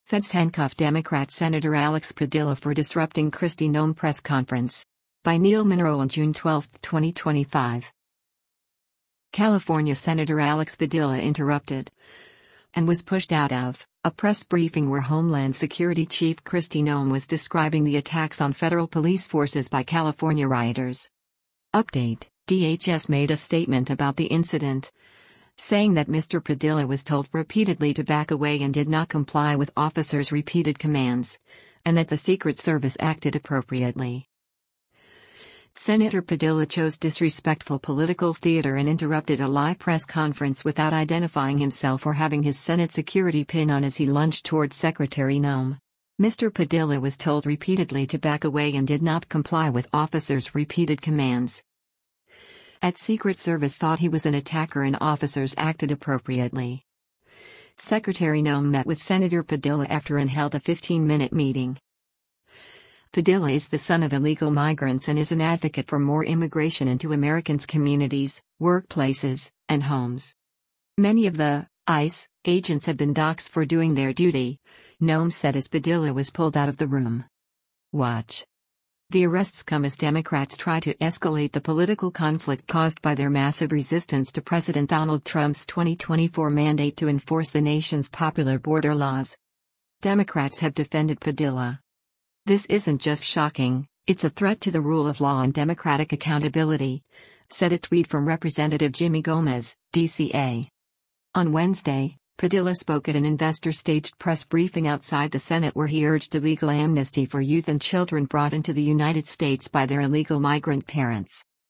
California Sen. Alex Padilla interrupted — and was pushed out of — a press briefing where Homeland Security chief Kristi Noem was describing the attacks on federal police forces by California rioters.
“Many of the [ICE] agents have been doxxed for doing their duty,” Noem said as Padilla was pulled out of the room.